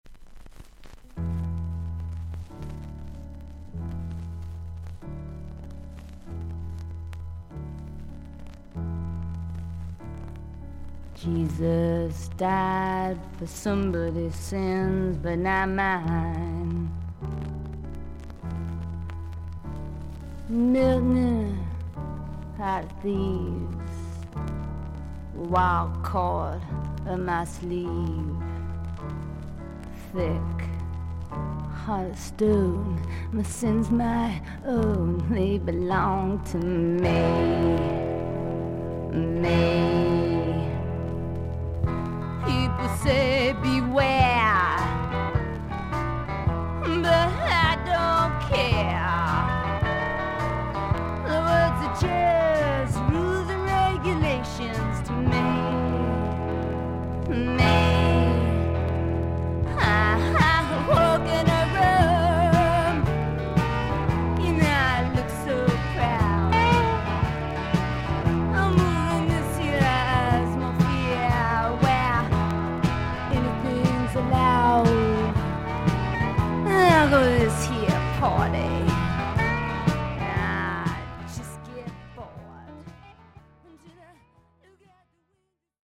少々軽いパチノイズの箇所あり。少々サーフィス・ノイズあり。クリアな音です。